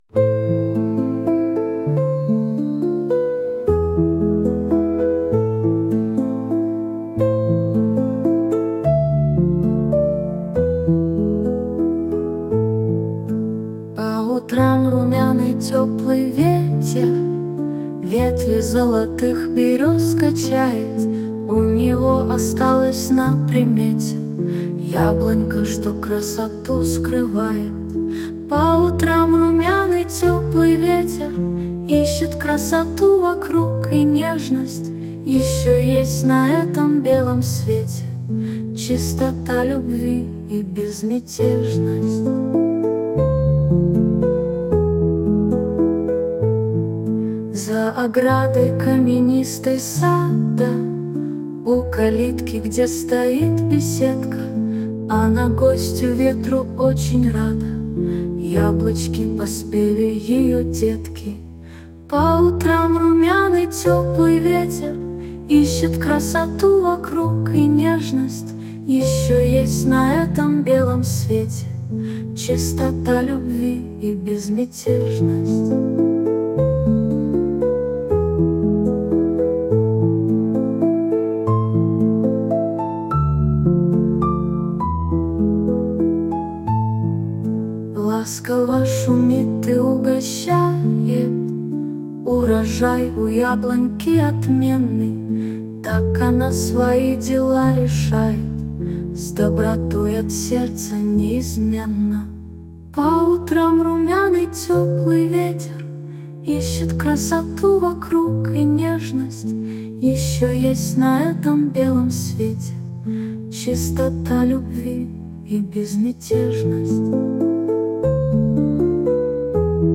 ТИП: Пісня
СТИЛЬОВІ ЖАНРИ: Ліричний
12 12 12 Ніжний голос підкреслює всю красу поезії!